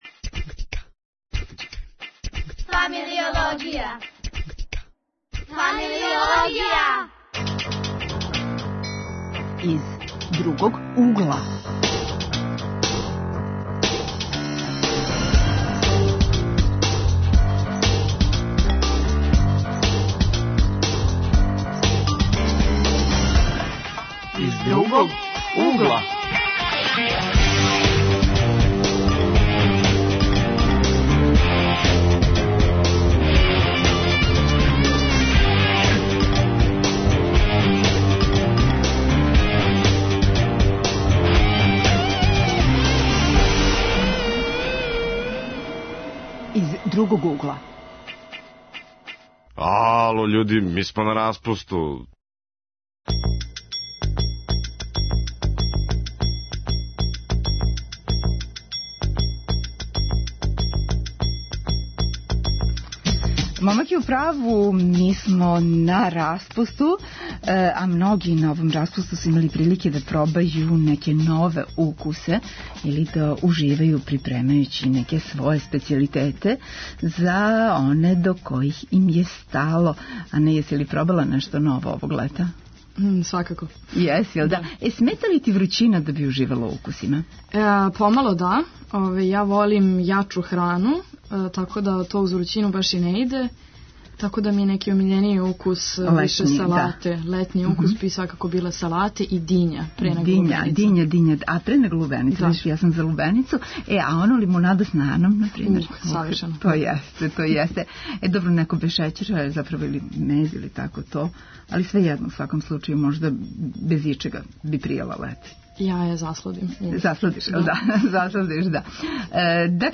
Тема: летњи укуси и мириси, гости - млади који су овог лета имали прилике да пробају храну разних земаља и да прикажу своју ...